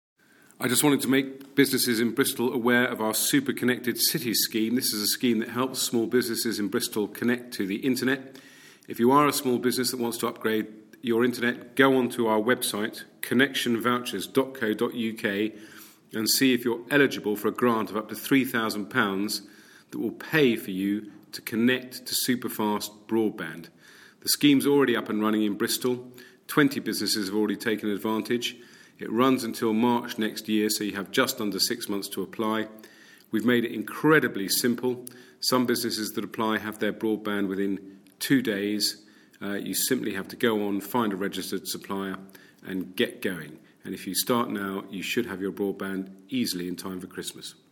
Minister for Culture & the Digital Economy, Ed Vaizey, encourages small businesses in Bristol to take advantage of the free broadband grant in time for Christmas trading.